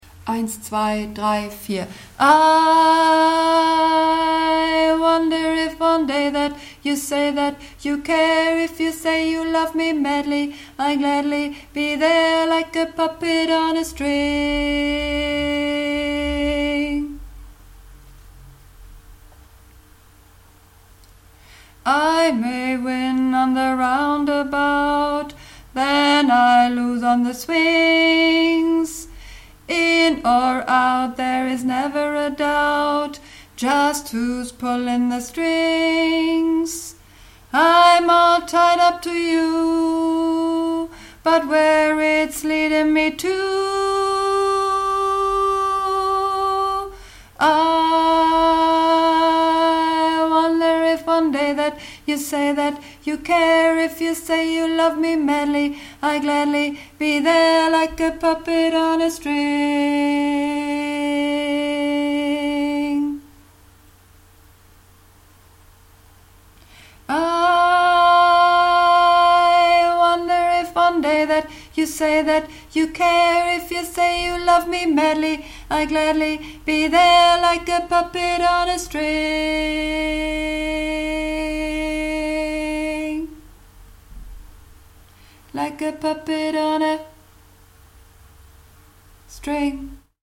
(Übungsaufnahmen)
Runterladen (Mit rechter Maustaste anklicken, Menübefehl auswählen)   Puppet On A String - Bass